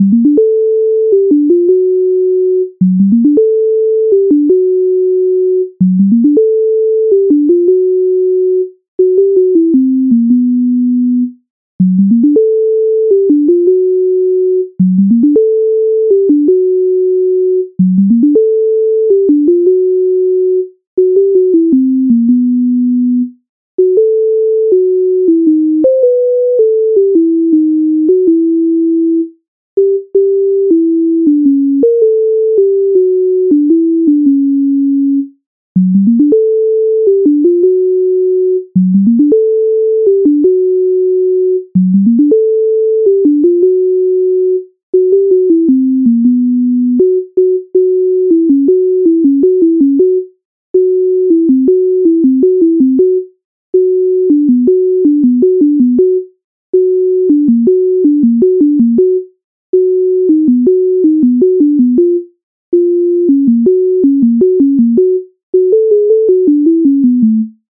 MIDI файл записаний в тональності G-dur